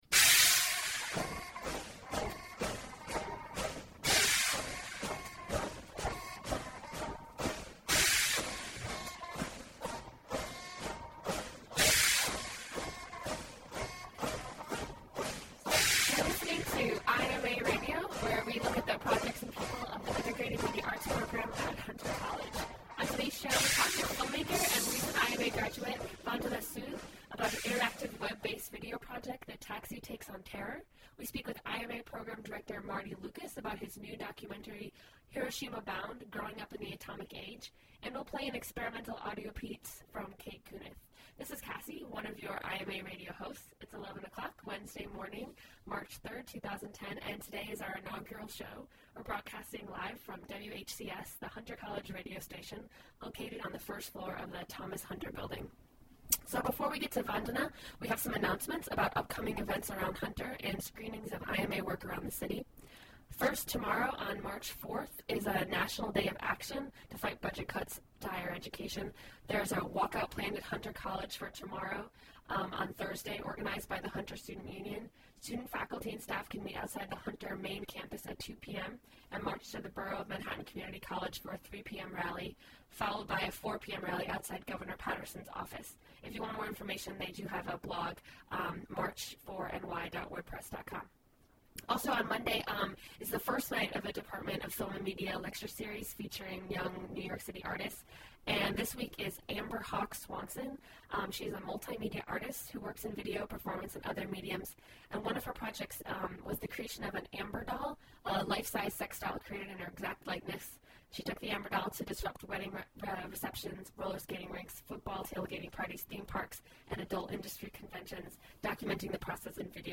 Check out the first IMA RADIO show on WHCS, Hunter College’s webcast radio station.